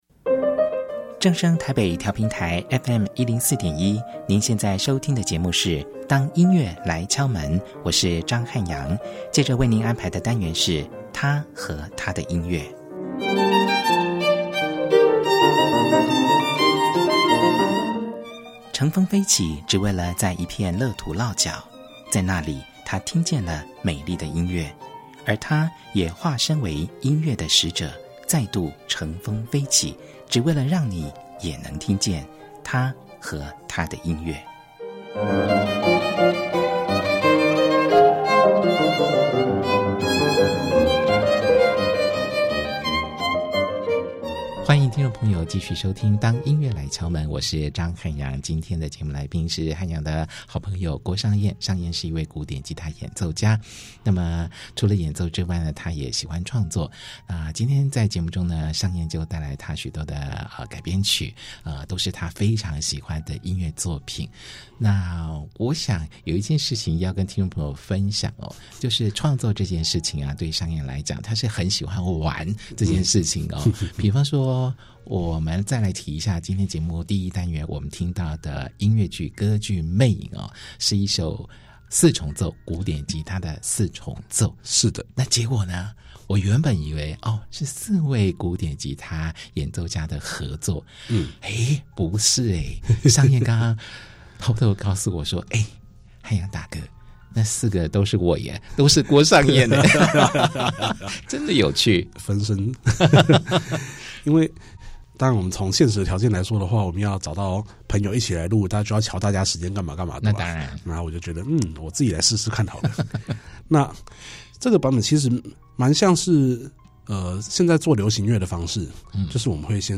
他將帶來兩首他用烏克麗麗彈奏的作品